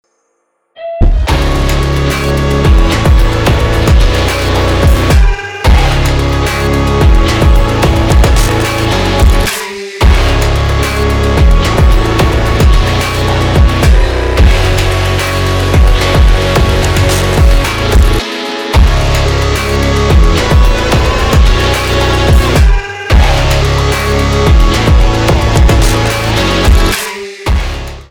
русский рэп
битовые , басы , качающие
громкие , без слов